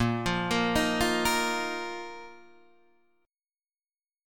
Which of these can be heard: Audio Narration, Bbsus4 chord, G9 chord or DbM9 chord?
Bbsus4 chord